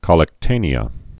(kŏlĕk-tānē-ə)